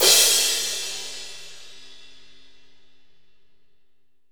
Index of /90_sSampleCDs/AKAI S6000 CD-ROM - Volume 3/Crash_Cymbal1/15-18_INCH_AMB_CRASH
18AMB CRS2-S.WAV